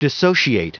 Prononciation du mot dissociate en anglais (fichier audio)
Prononciation du mot : dissociate